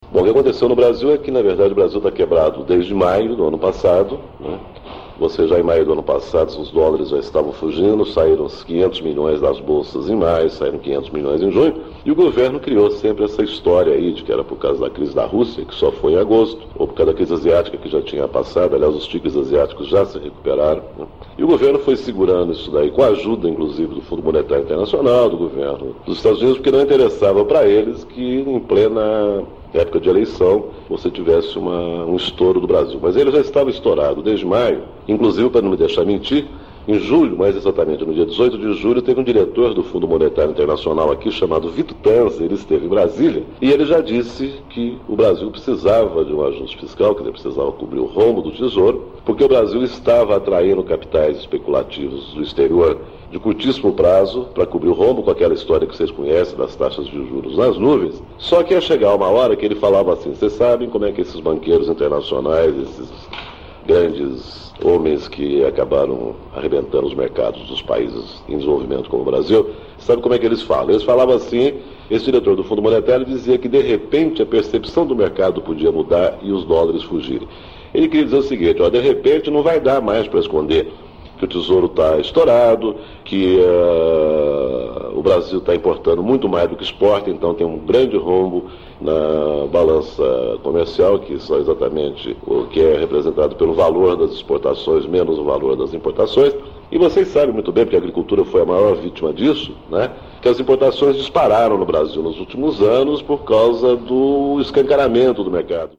Esta é a segunda carta falada de Aloysio Biondi para o programa A Voz da Contag, gravada para a edição 302, de 30 de julho de 1998, desta vez para (...)